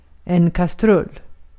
Ääntäminen
IPA : /pɒt/